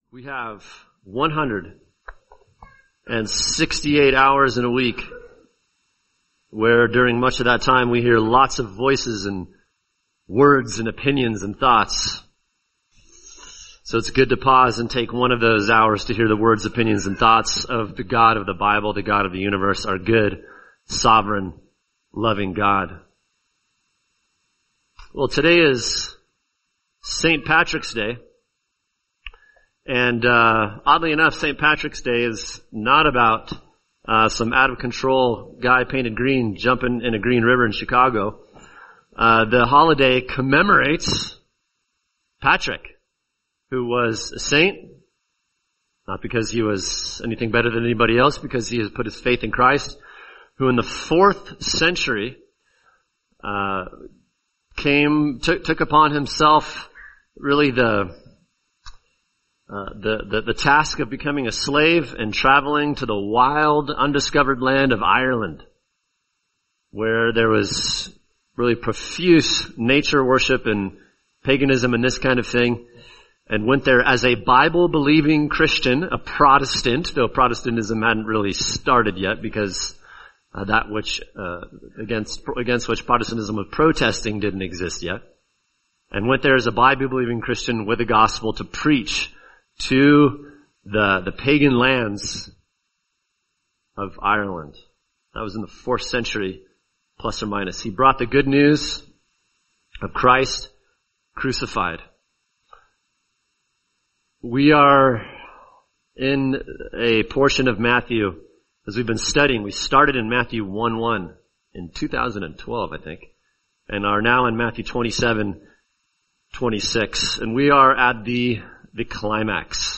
[sermon] Matthew 27:26-31 The Ironies of Christ’s Sufferings | Cornerstone Church - Jackson Hole